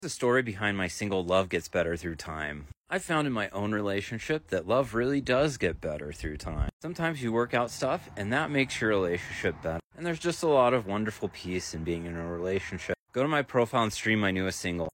piano single